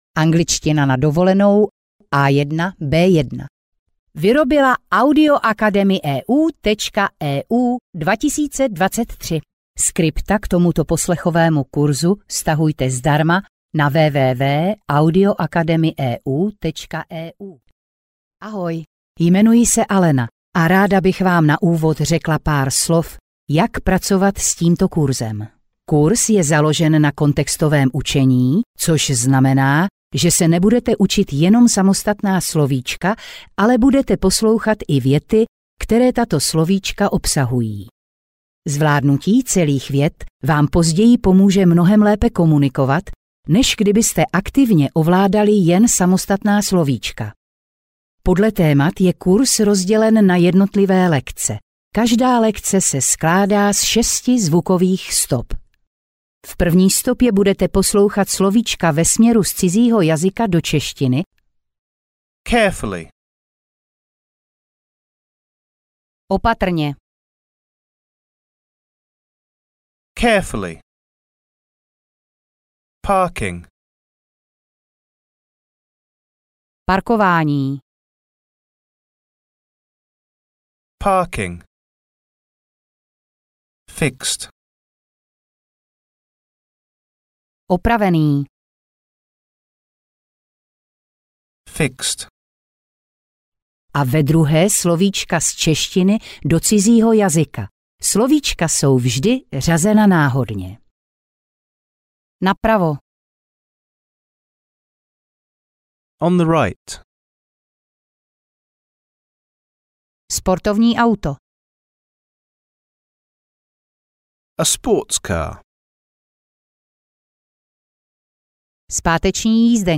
Angličtina na dovolenou A1-B1 audiokniha
Ukázka z knihy